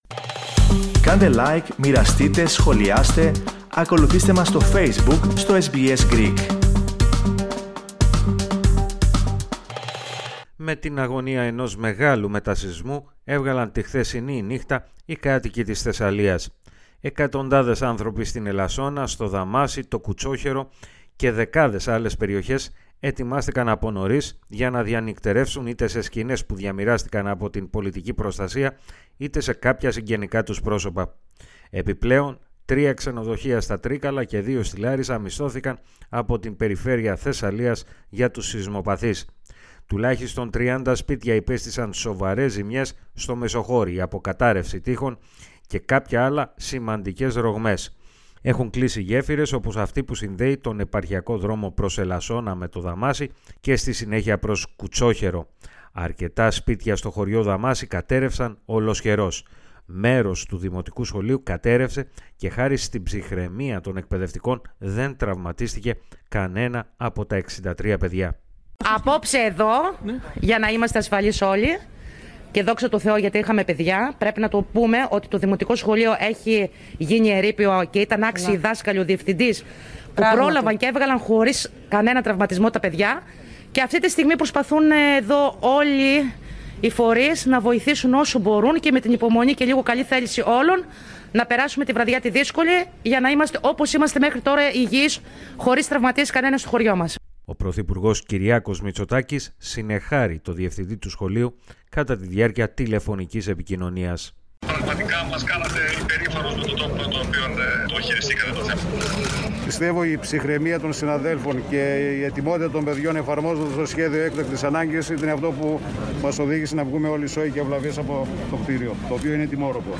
Ιδιαίτερα στο Μεσοχώρι και στο Δαμάσι, της Λάρισας, οι καταστροφές είναι μεγάλες, ωστόσο, ο ισχυρός σεισμός δεν προκάλεσε ανθρώπινες απώλειες. Περισσότερα ακούστε στην αναφορά